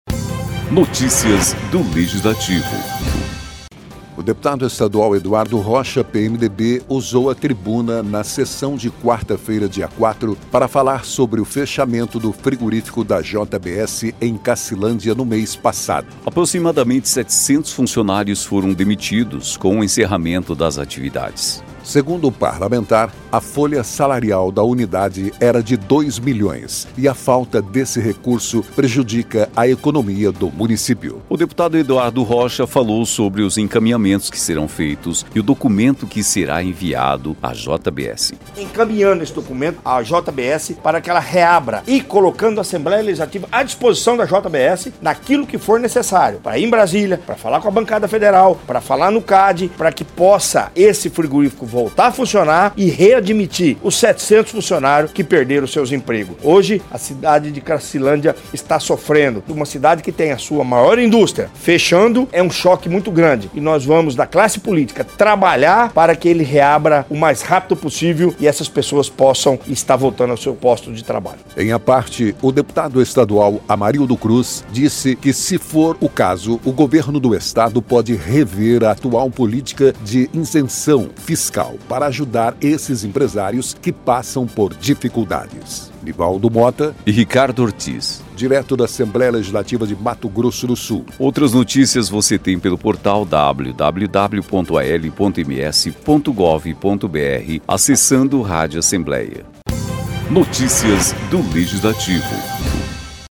O deputado estadual Eduardo Rocha (PMDB) usou a tribuna na sessão desta quarta-feira (4/4) para falar sobre o fechamento do frigorífico da JBS em Cassilândia no mês passado.